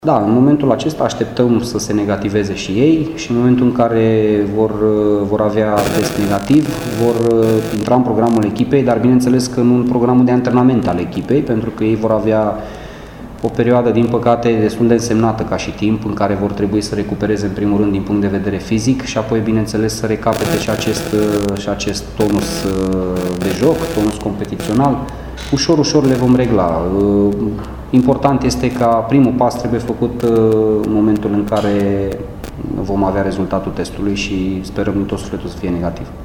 Laszlo Balint spune că, deși cu toții pot reveni la antrenamente, va mai trece timp până să și poată debuta în Liga I: